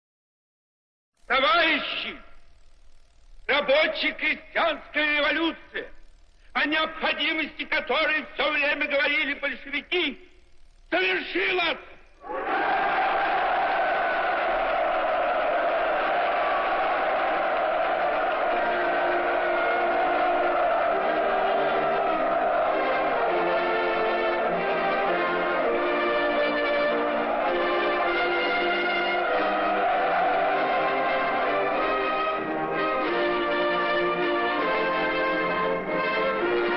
(voz de Lenin